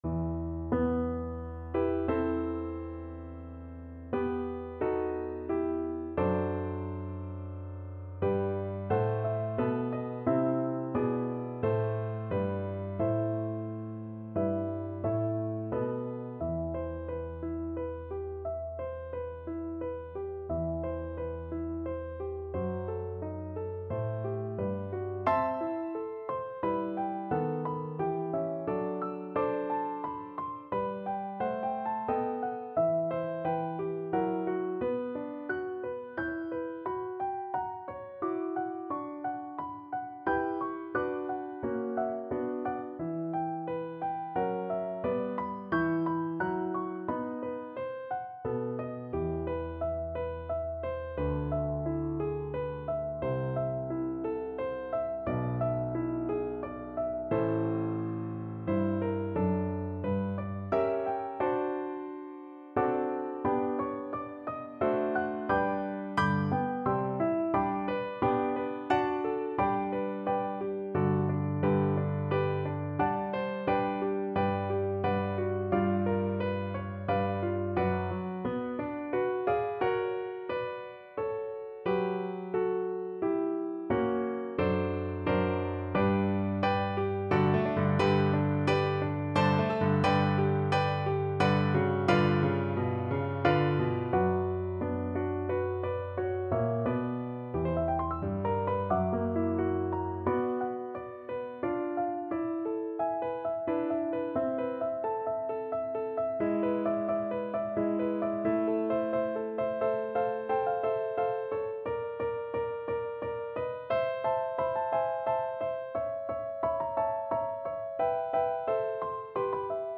3/4 (View more 3/4 Music)
=88 Nicht schnell =100
Classical (View more Classical Cello Music)